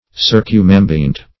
Search Result for " circumambient" : The Collaborative International Dictionary of English v.0.48: Circumambient \Cir`cum*am"bi*ent\, a. [Pref. circum- + ambient.]
circumambient.mp3